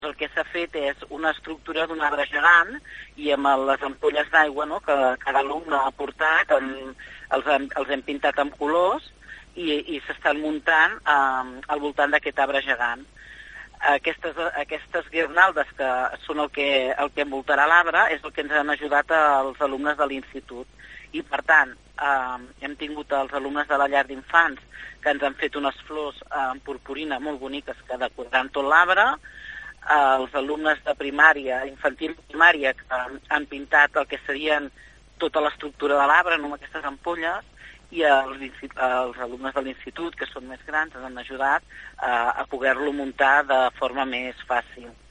Ho explica Susanna Pla, regidora d’Educació de l’Ajuntament de Palafolls.